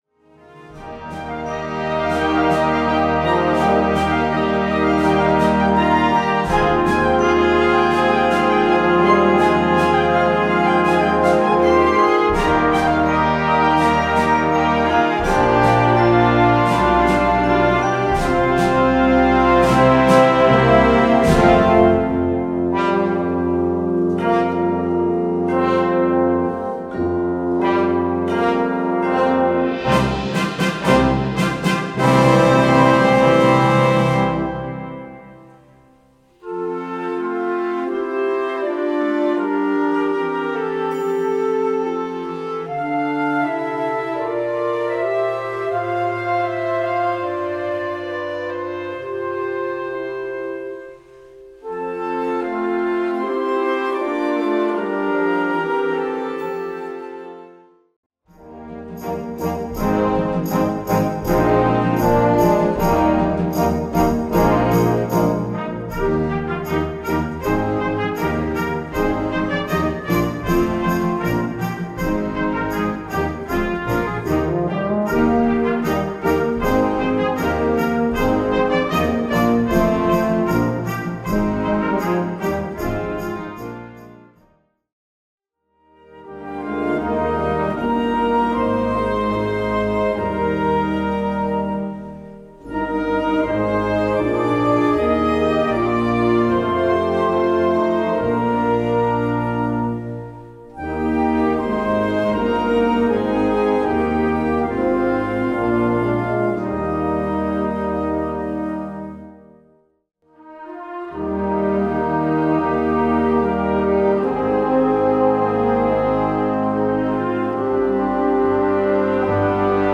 Gattung: Weihnachtslied
Besetzung: Blasorchester